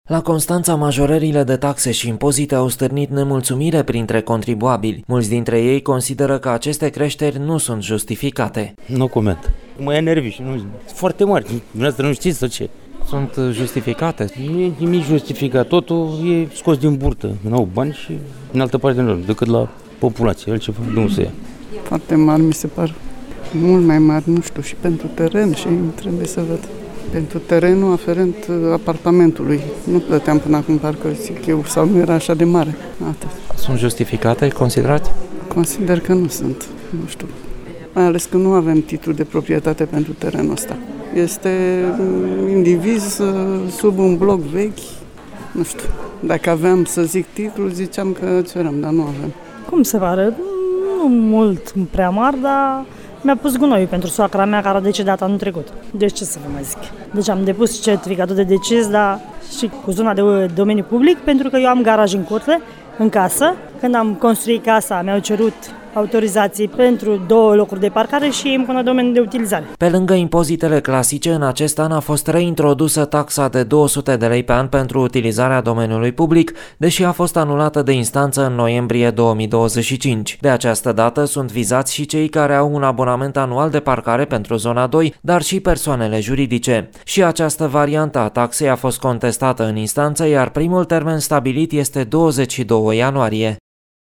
Decât de la populație”, este de părere un constănțean.
Trebuie să văd și pentru terenul afferent apartamentului”, ne-a declarat o constănțeancă.